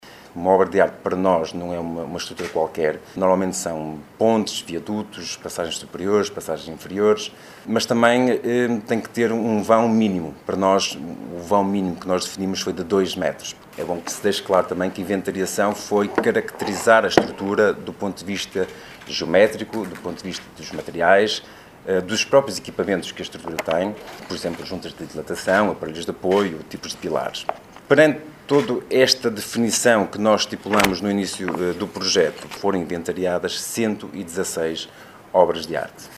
Estes números foram dados a conhecer na apresentação pública do relatório final do “Projeto Reconhecer”, um estudo encomendado, em 2019, pelo Município barcelense e que envolveu a Universidade Lusófona de Humanidades e Tecnologias e o Laboratório Nacional de Engenharia Civil.